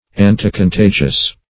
Search Result for " anticontagious" : The Collaborative International Dictionary of English v.0.48: Anticontagious \An`ti*con*ta"gious\, a. (Med.) Opposing or destroying contagion.